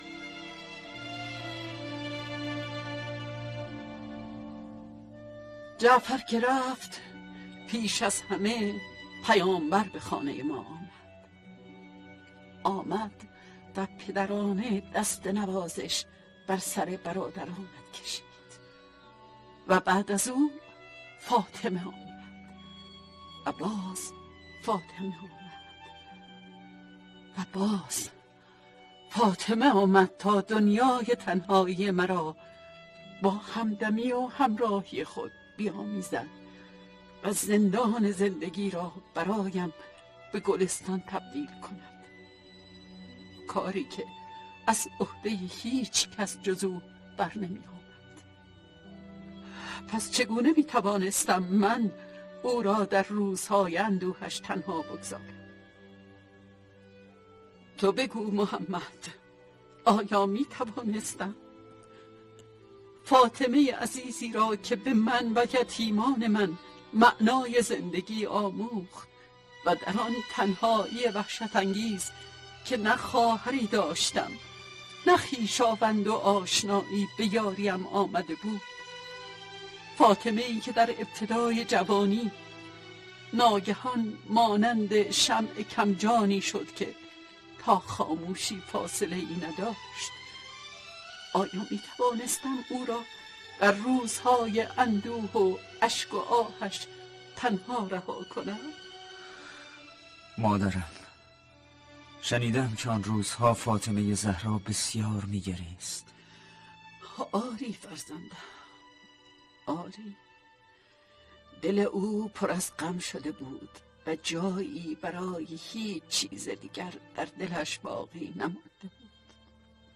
«اسماء» اولین تابوتی را که در اسلام ساخته‌شده برای حضرت فاطمه(س) ساخت و این شخصیت را با تمام قدرت و مهربانی بی‌مانندی که در همنشینی و مراقبت از حضرت فاطمه(س) داشت را زنده‌نام ژاله علو در این اثر نمایشی با ظرافت بی‌بدیلی نقش‌آفرینی کرده ‌است.